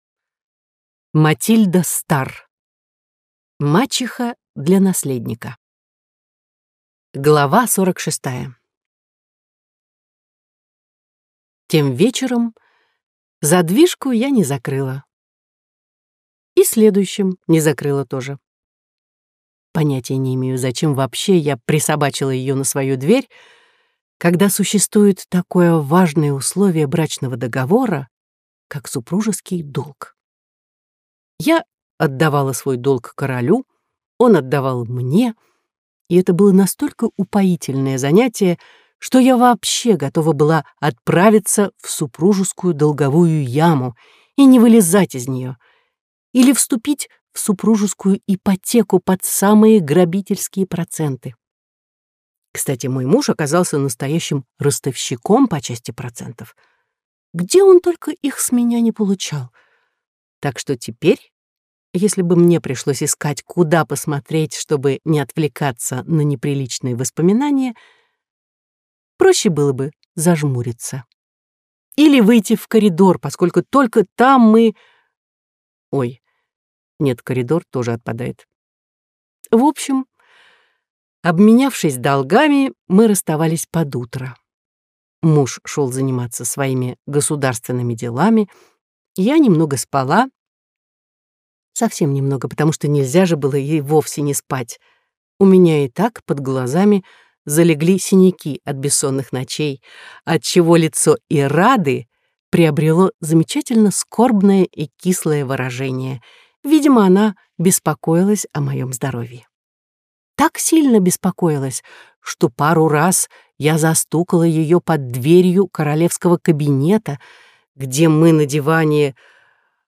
Аудиокнига Мачеха для наследника - купить, скачать и слушать онлайн | КнигоПоиск